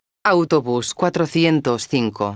megafonias exteriores
autobus_405.wav